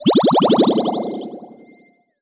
potion_bubble_effect_brew_04.wav